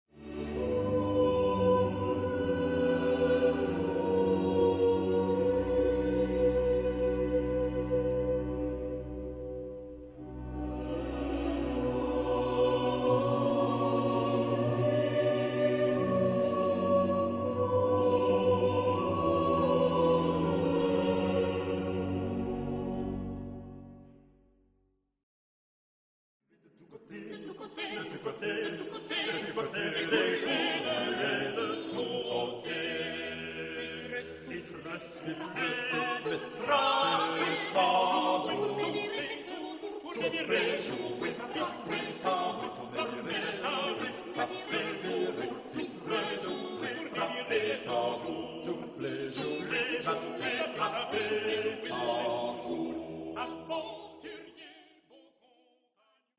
L'audio propone un frammento di un corale di J. S. Bach (1) e di una chanson di C. Jannequin (2).